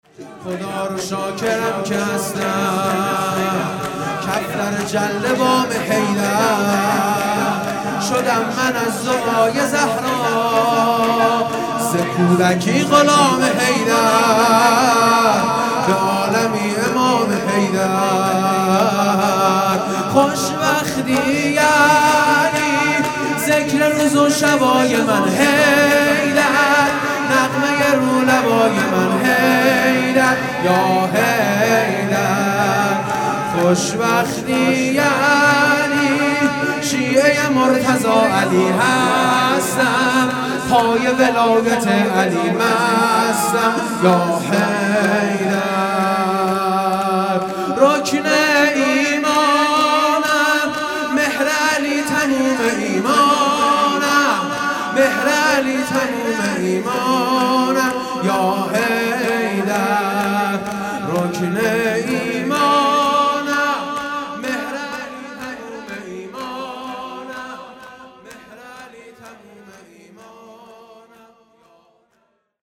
شهادت حضرت زهرا(ُس) - سه شنبه10بهمن1396